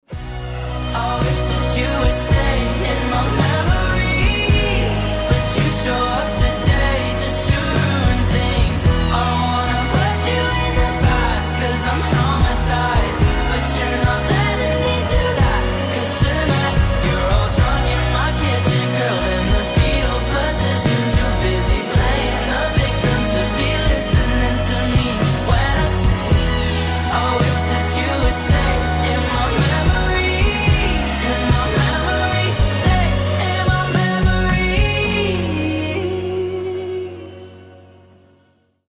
• Качество: 320, Stereo
красивый мужской голос
alternative
легкий рок
баллады